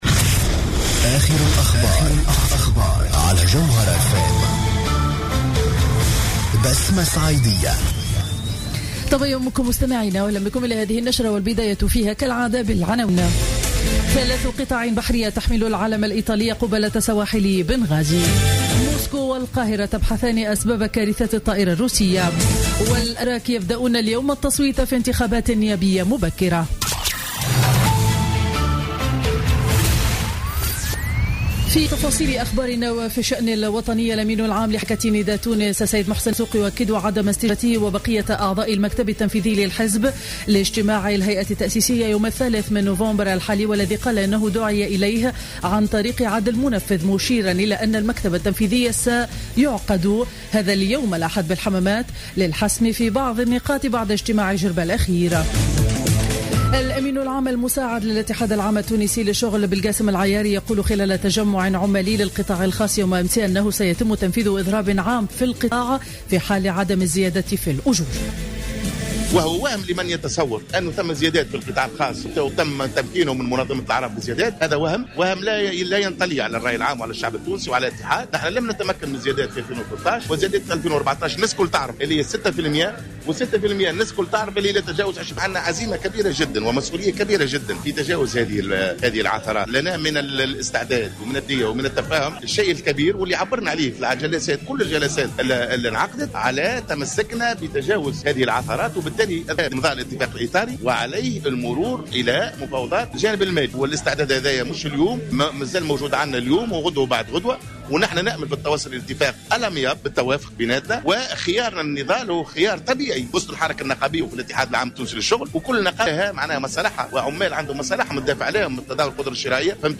نشرة أخبار السابعة صباحا ليوم الأحد غرة نوفمبر 2015